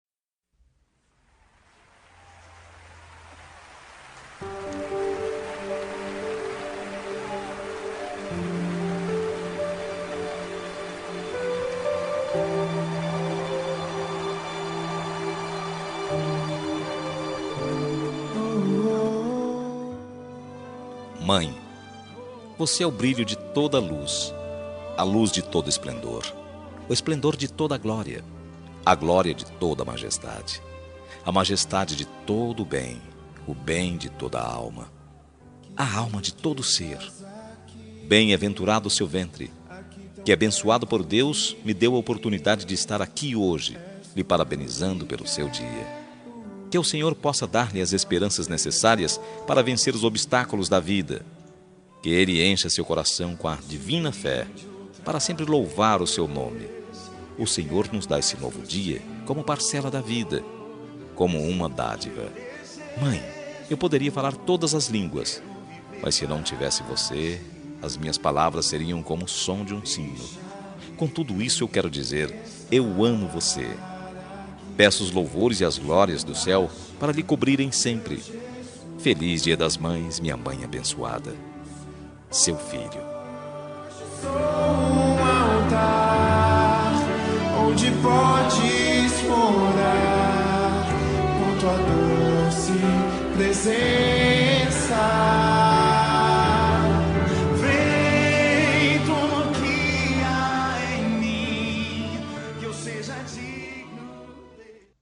Dia das Mães – Para Mãe Evangélica – Voz Feminina – Cód: 6609